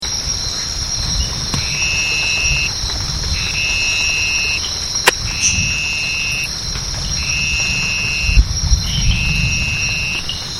Common names: Elegant Narrowmouthed Frog / Toad
Call from within bunches of grass or beneath thick vegetation.
Call is high pitched and prolonged, almost like the buzzing of an insect - baaaaaaaaa.